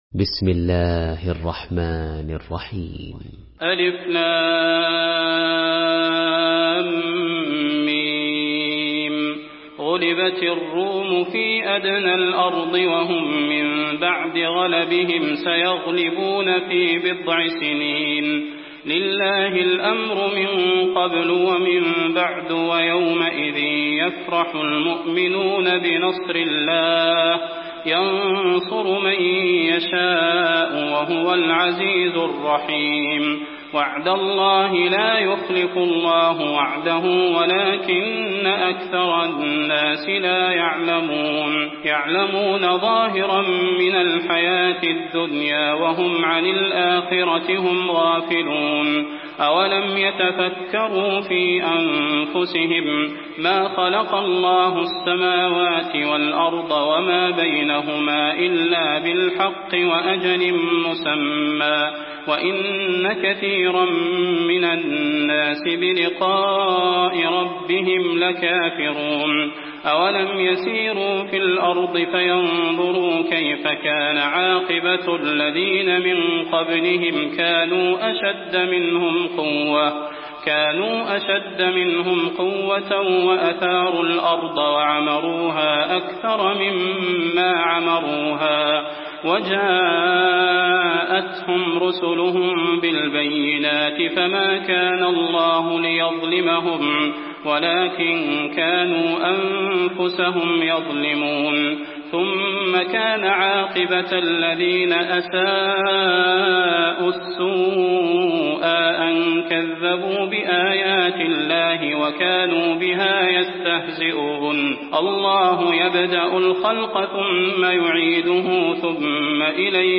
Surah Ar-Rum MP3 by Salah Al Budair in Hafs An Asim narration.
Murattal Hafs An Asim